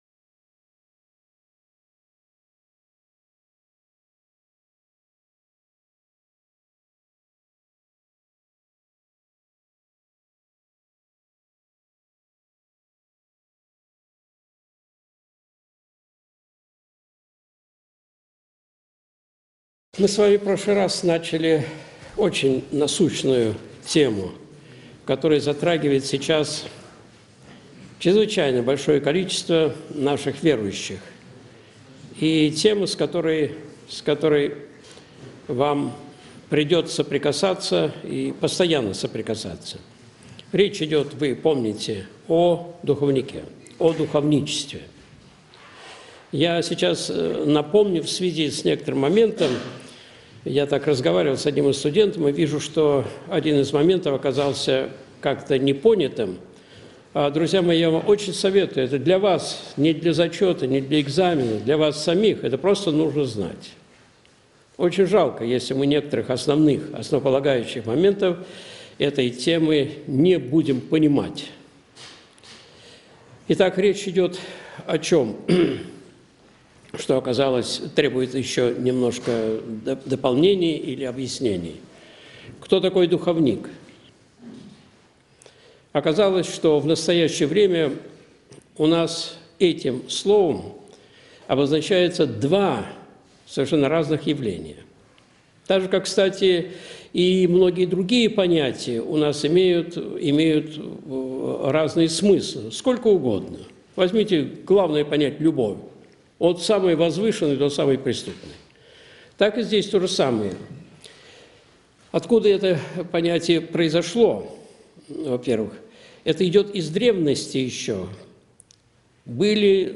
Видеолекции протоиерея Алексея Осипова